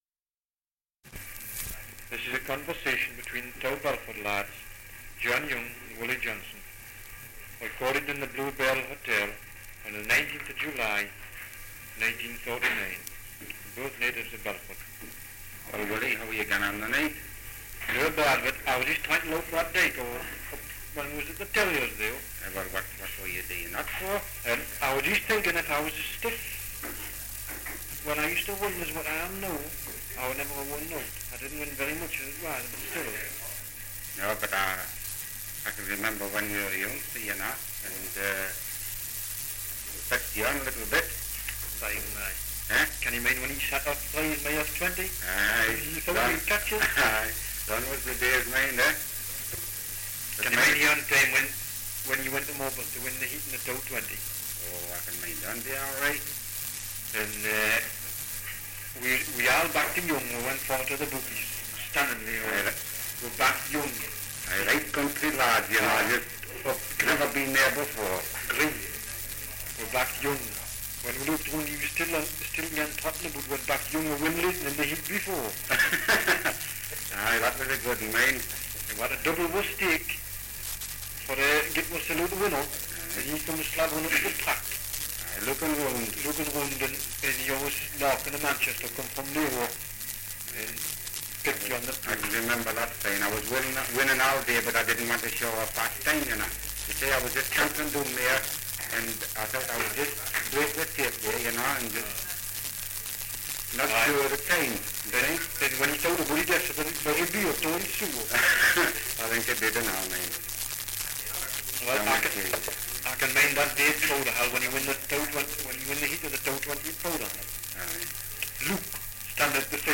Dialect recording in Belford, Northumberland
78 r.p.m., cellulose nitrate on aluminium